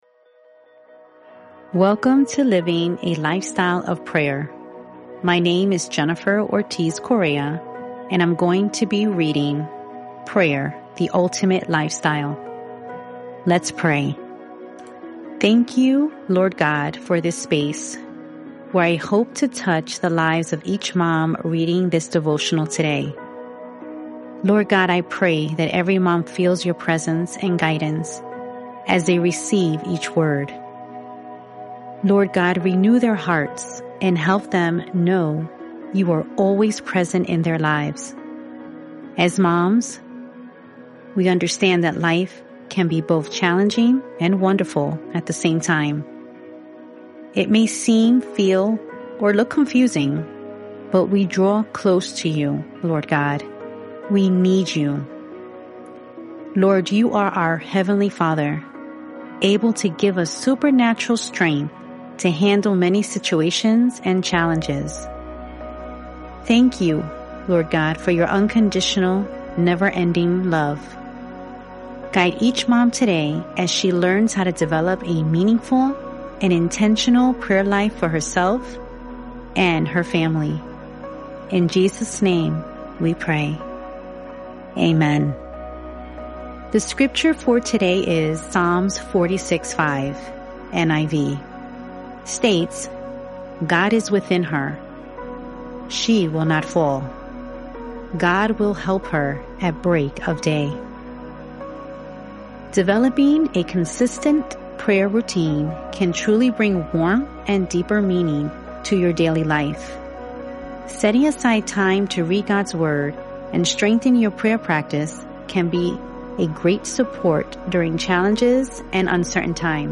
Living a Lifestyle of Prayer: 30-Day Audio Devotional for Moms